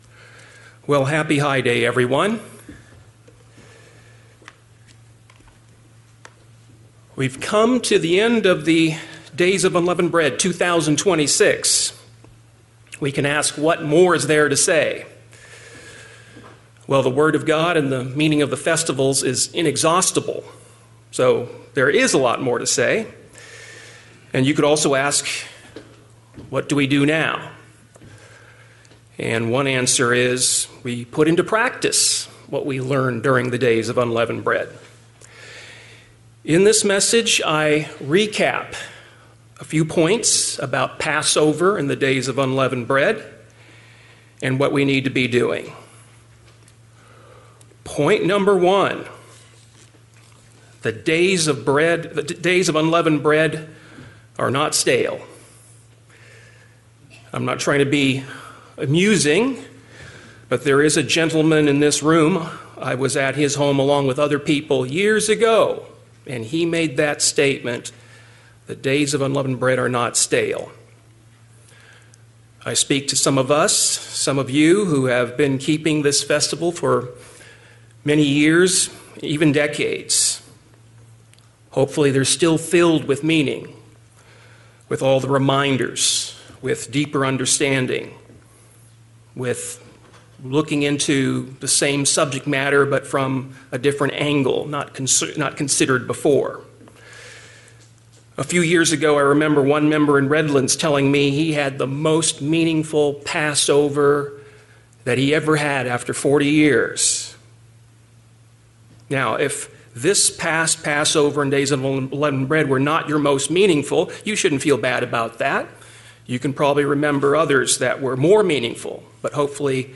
In this message, we cover 7 points regarding Passover and the Days of Unleavened Bread.